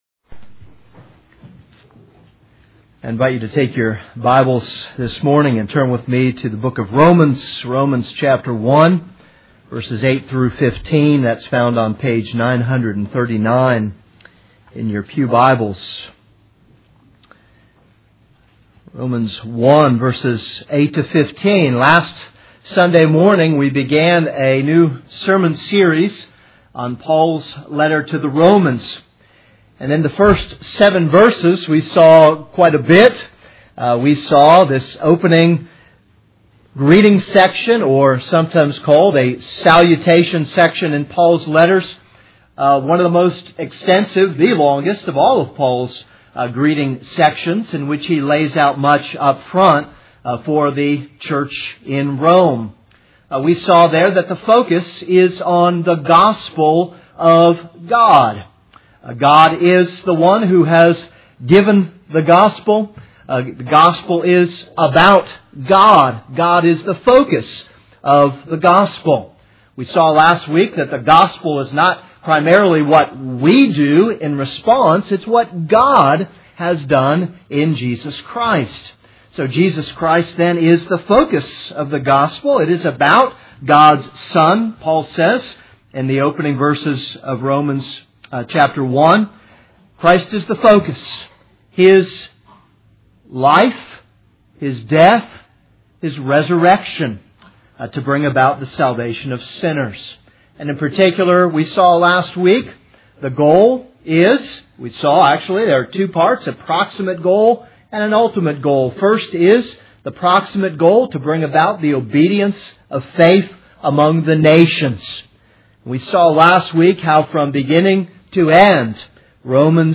This is a sermon on Romans 1:8-15.